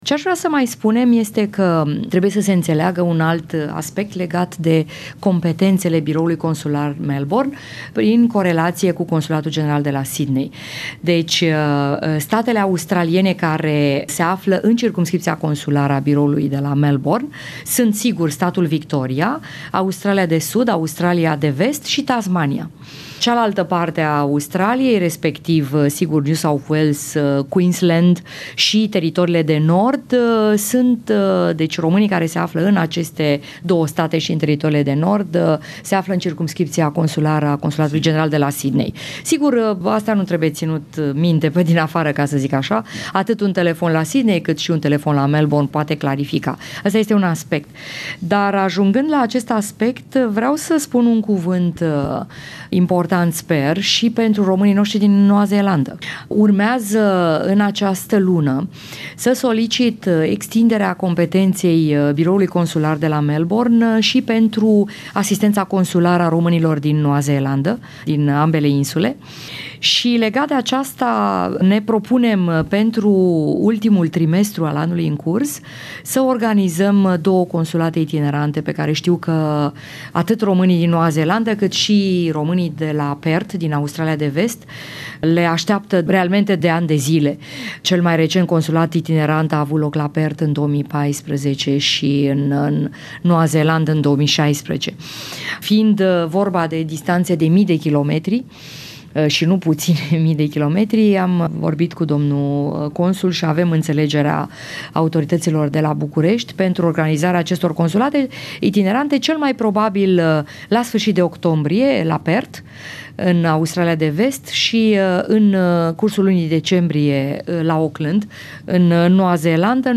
Interview with Her Excelency Nineta Barbulescu, Romanian Ambassador in Australia and New Zealand and the Consul Daniel Besliu, at the new Romanian Consular Bureau, in Melbourne - pt.2
interview_consular_bureau_melbourne_pt_2_fin_-_13.10.mp3